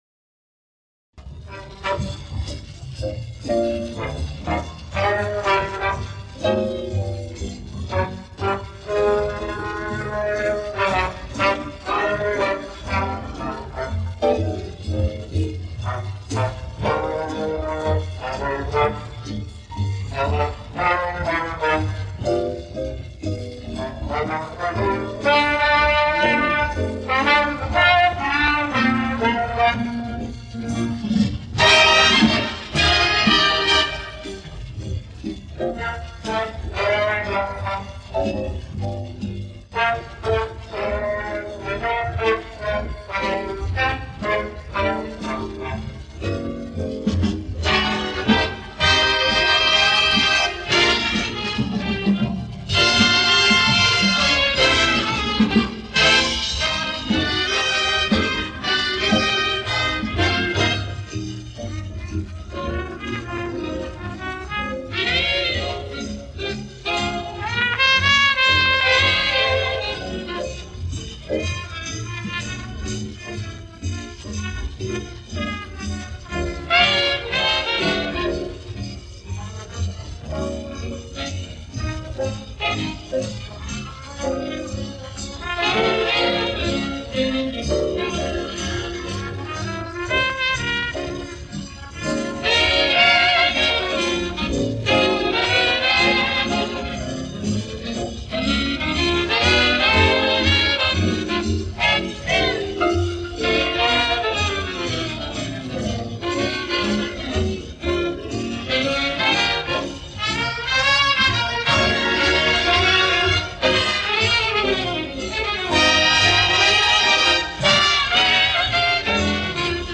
Инструментал 2